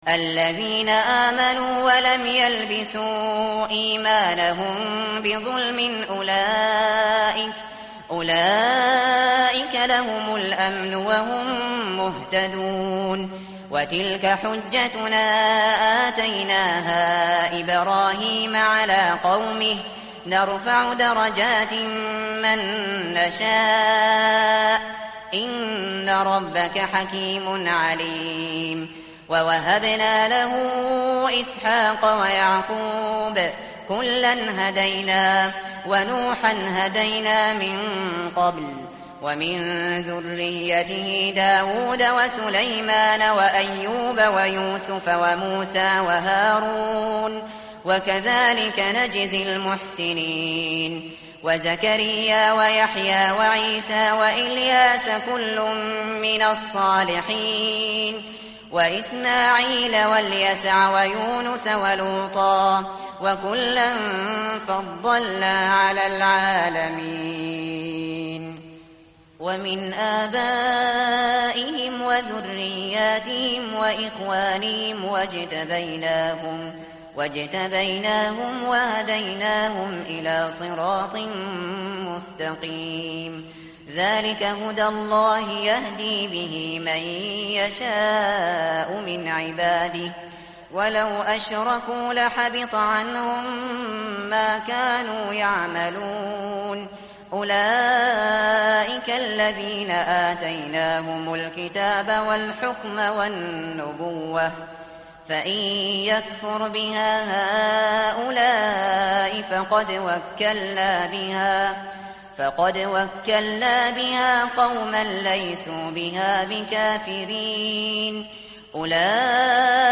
tartil_shateri_page_138.mp3